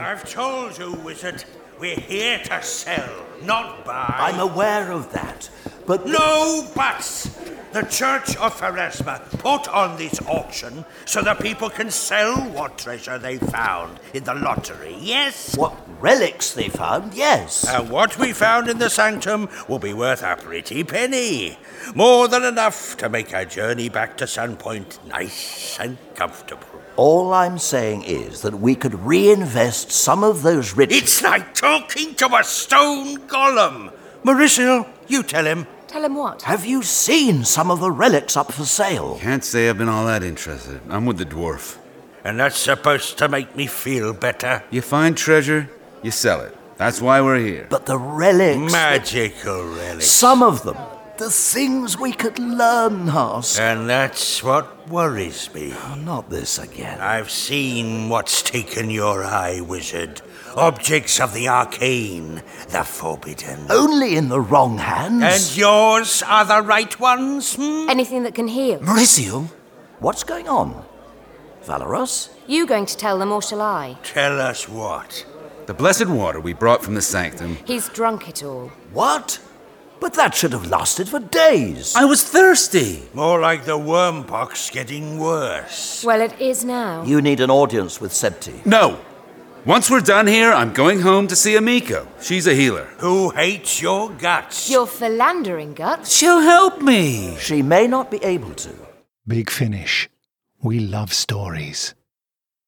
For The Love Of StoriesBig Finish produce fantastic full-cast audio dramas for CD and download.
Recorded at: Soundhouse Studios